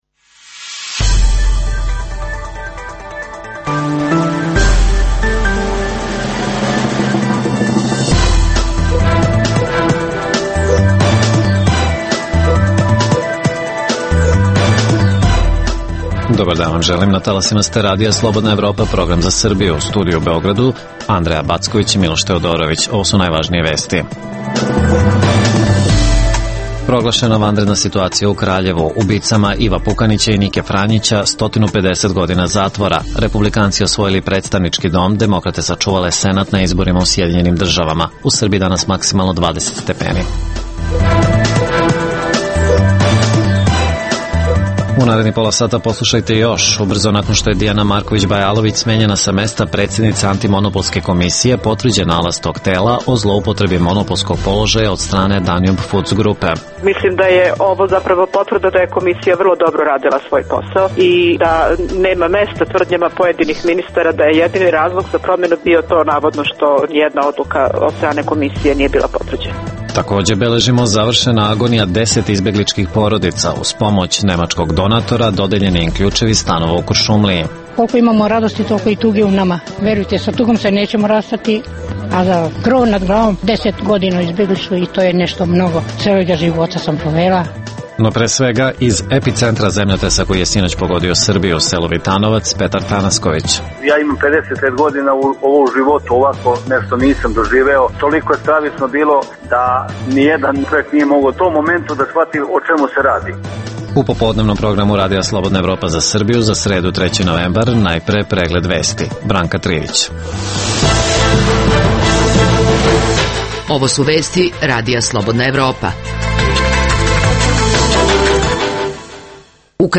U ovoj emisiji možete čuti: - Zemljtres u Kraljevu izvešavamo sa lica mesta, iz sela Vitanovac, epicentra udara. - Republikanci osvojili ubedljivu vecinu u Predstavnickom domu Kongresa SAD.